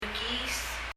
mekiis　　　　[mɛki:s]　　　　　起きる　wake up
発音